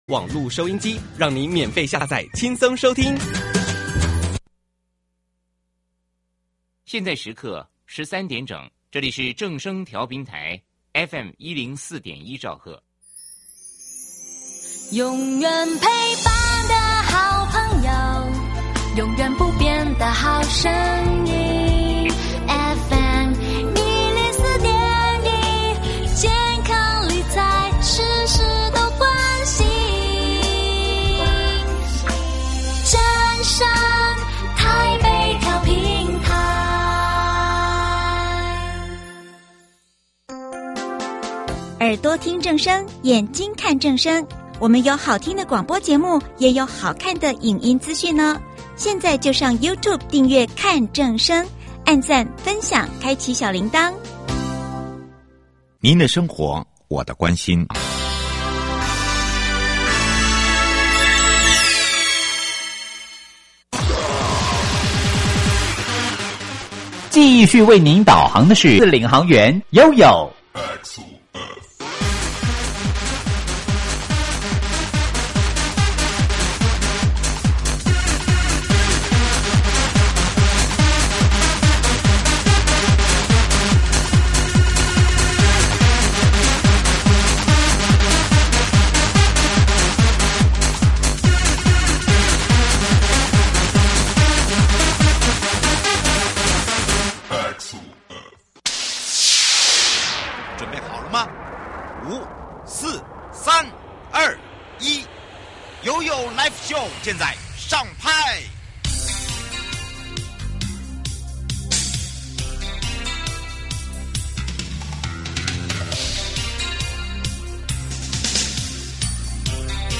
受訪者： 營建你我他 快樂平安行~七嘴八舌講清楚~樂活街道自在同行!
1.國土署都市基礎工程組 2. 北投區振華里郭崇儀里長(二) 節目內容： 1.國土署都市基礎工程組 2.北投區振華里郭崇儀里長(二)